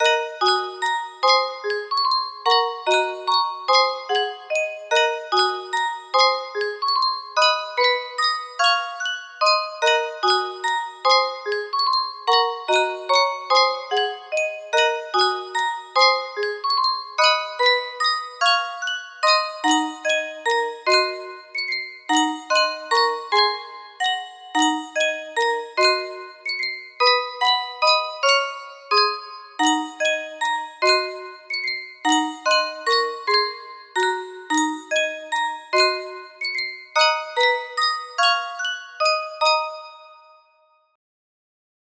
Roots music box melody
Full range 60